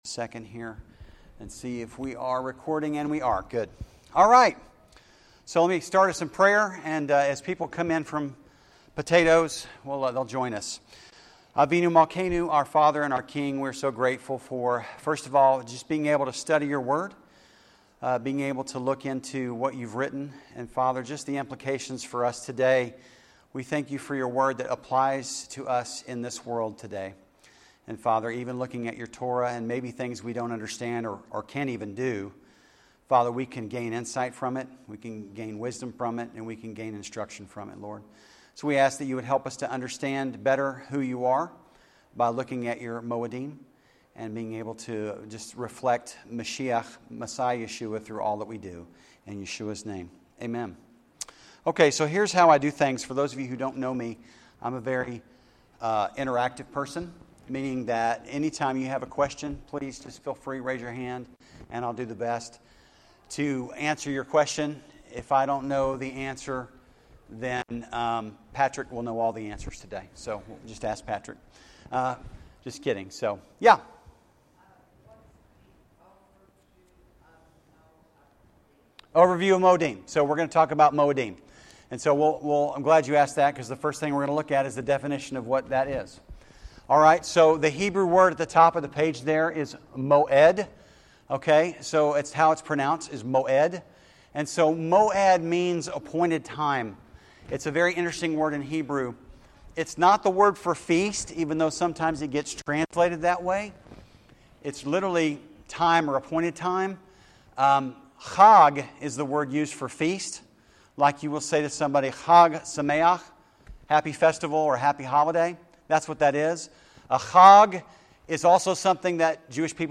This Podcast contains our weekly sermon messages, but you can watch entire services on our website.